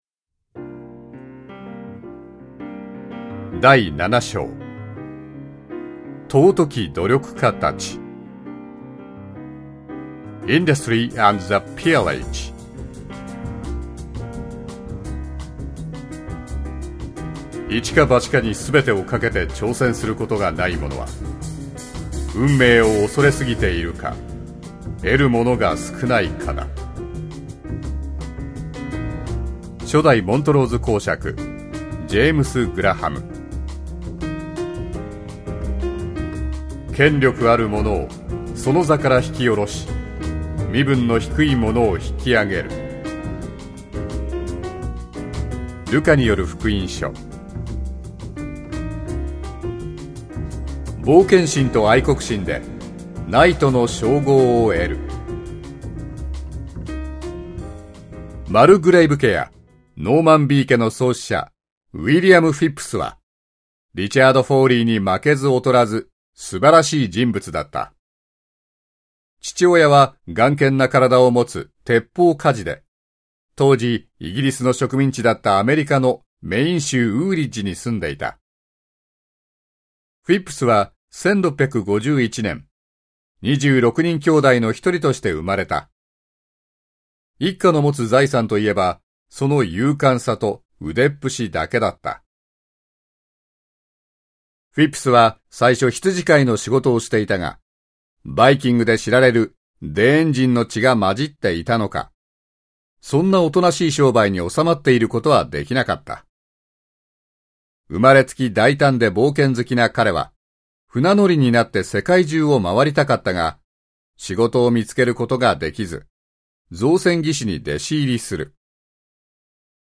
本オーディオブックは、1858年にイギリスで上梓された『自助論』の改訂版を現代語にて全文を完全新訳し、朗読したものである。